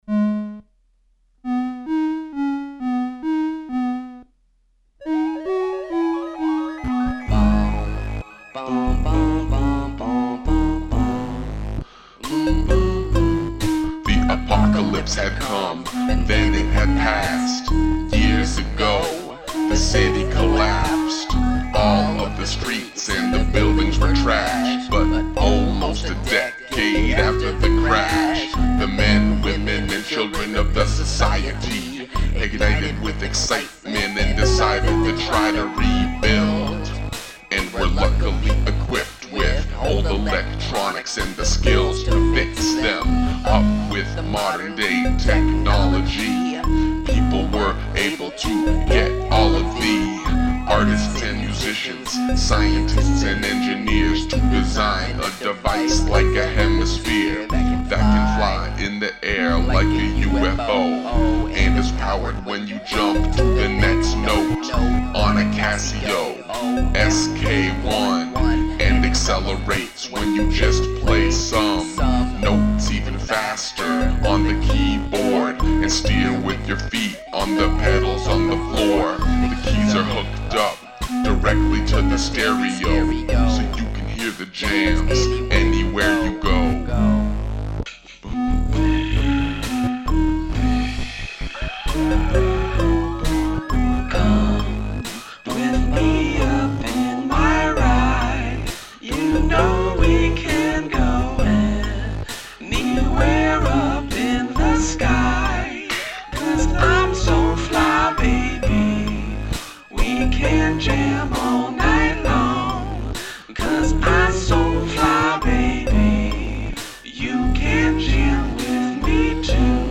The initial idea was to create a song using only a small monophonic toy Casio keyboard (not an SK-1).
I played around a lot with pitch shifting and running sounds through different types of amps, like running the instrumental out through a set of speakers and than recording the sound and blending it with the dry line in audio.
Or, running the vocals through a tube amp and letting it feed back ever so slightly.
I also played some subtle ride cymbal and scratched a bass synth tone.
The group chorus is a lot of fun.